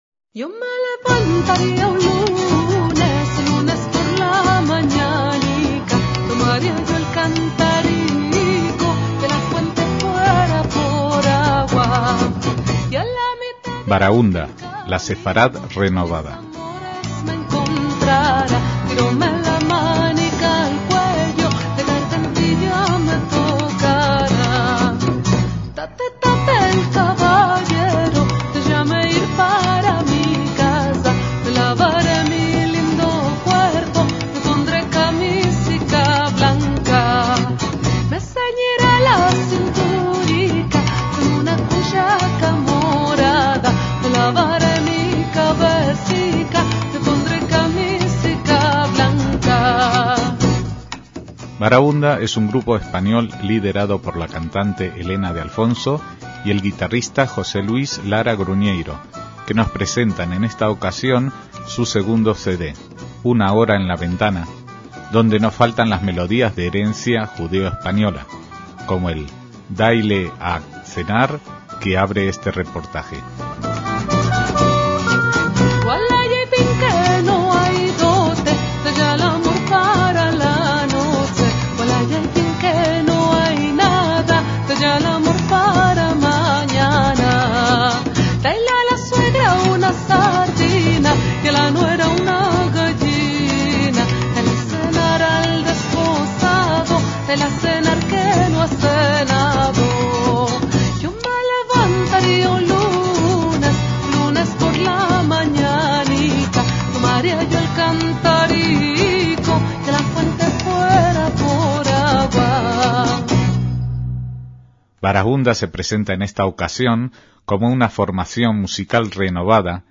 MÚSICA SEFARDÍ
una formación musical renovada con estructura de quinteto
guitarras
oboe
bajo
percusiones